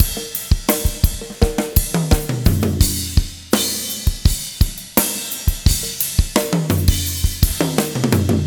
19 rhdrm85roll.wav